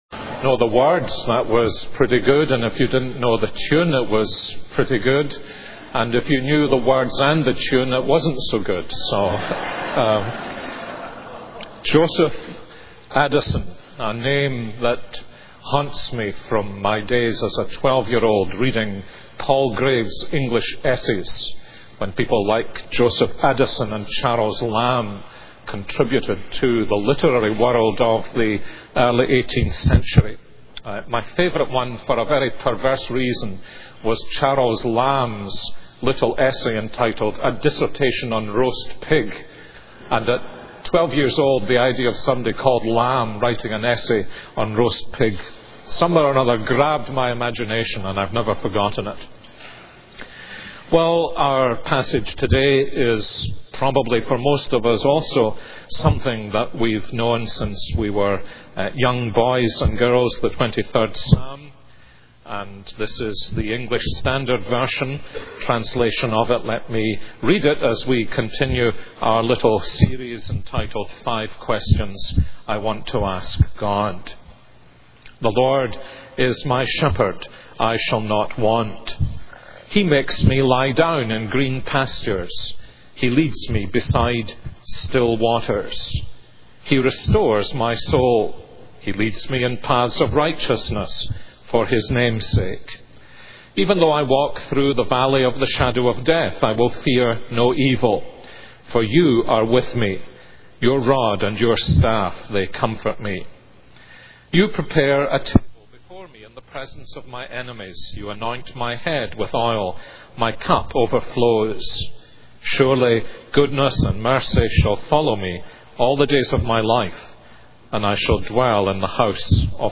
This is a sermon on Psalm 23.